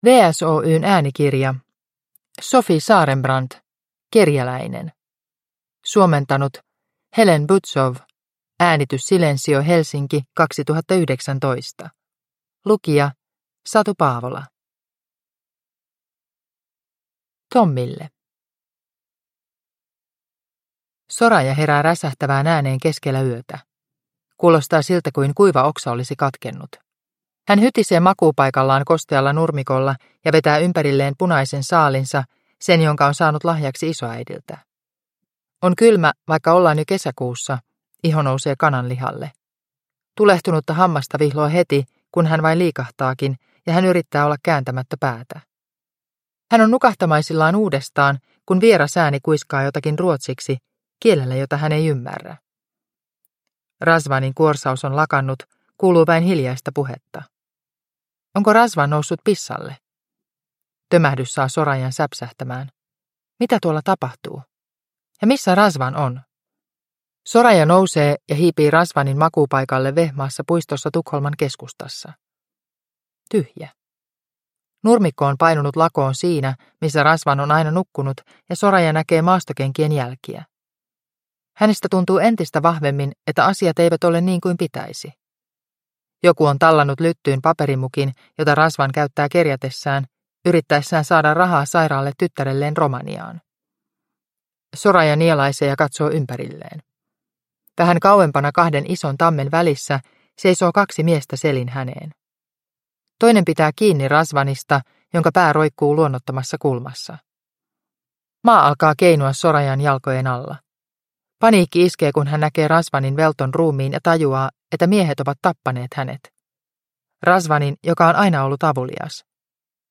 Kerjäläinen – Ljudbok – Laddas ner